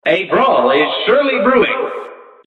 cuphead-narrator-a-brawl-is-surely-brewing.mp3